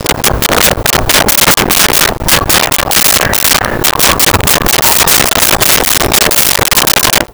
Chickens In A Barn 03
Chickens in a Barn 03.wav